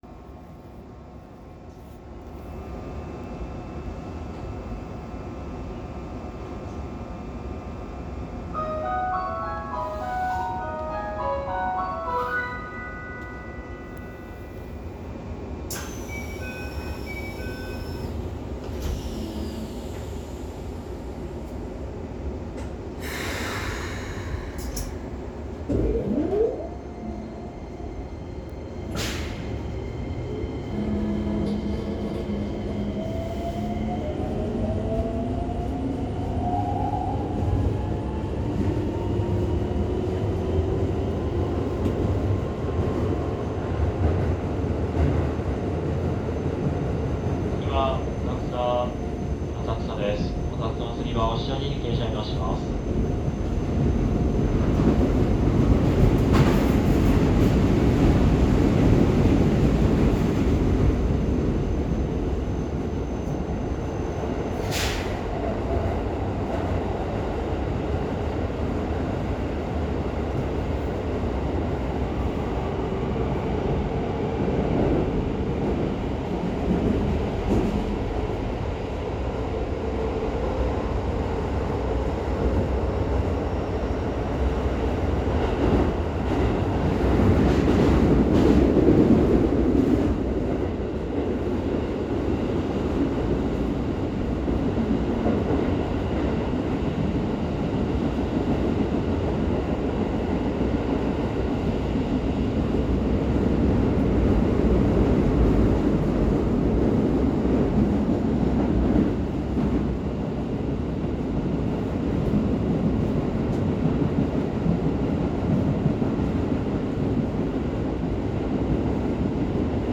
・1000形（東洋IGBT）走行音
【都営浅草線】東日本橋→浅草